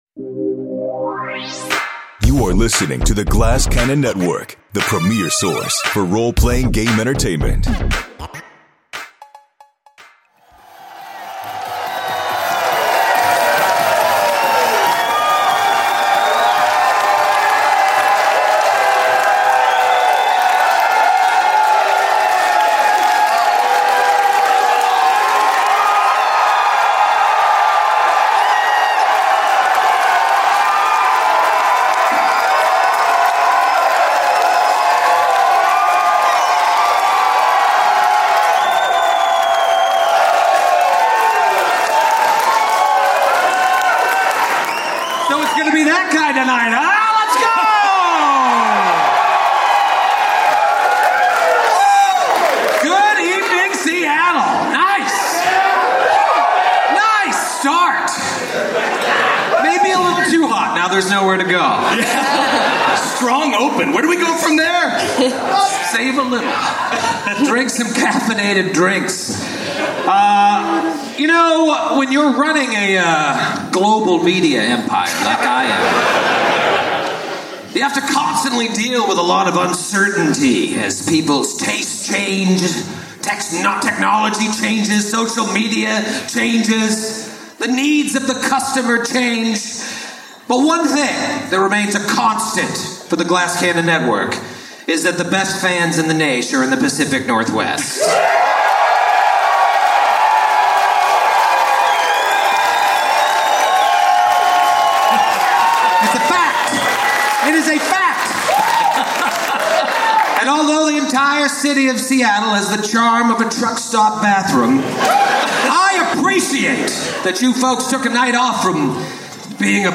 Note: We apologize for the poor audio. The mixing board audio was unfortunately corrupted.